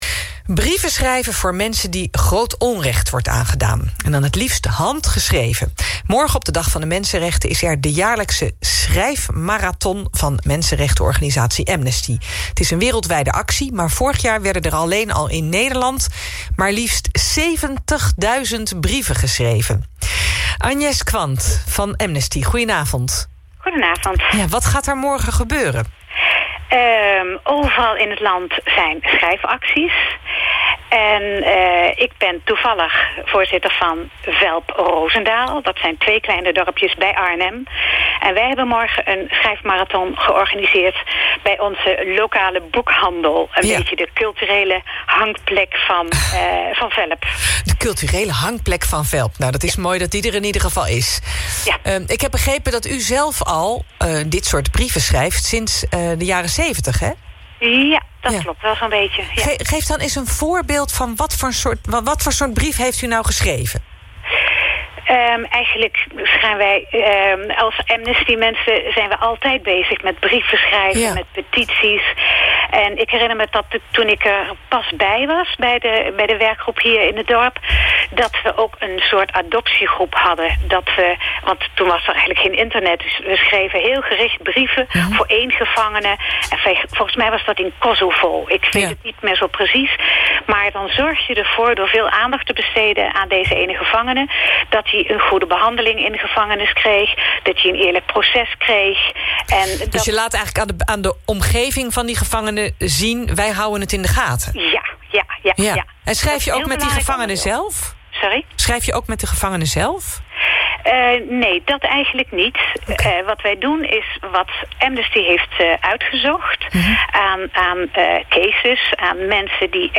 Amnesty Rheden Rozendaal - Interview